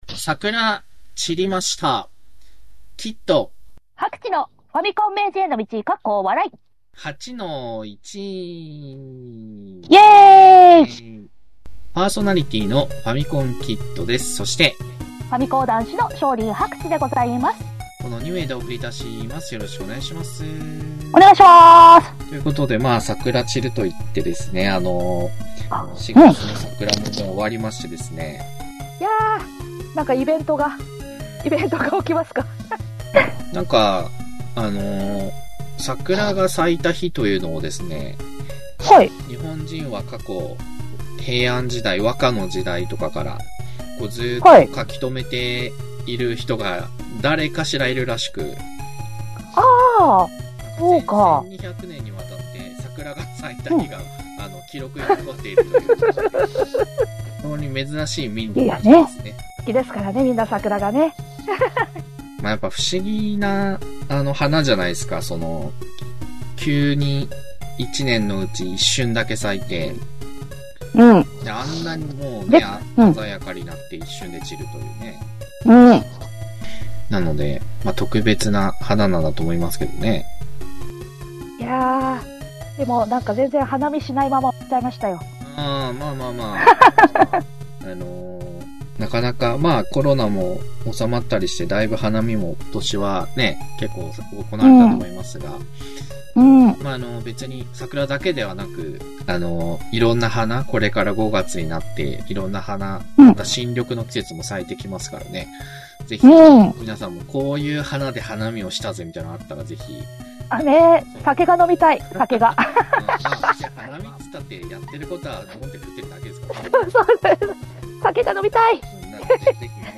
昭和っぽいテイストのインターネットラジオ、第19期8-1です！